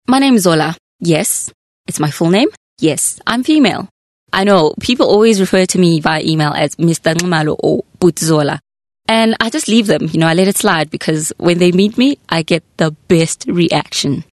animation, articulate, bright, commercial, confident, conversational, friendly, High Energy, Trendy
CONVERSATIONAL